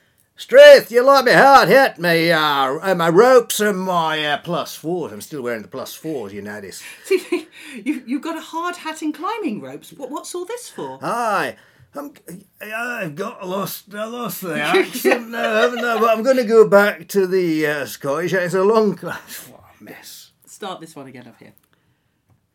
And just to prove that we’re fallible, here’s a funny out-take.
shriven-out-take.mp3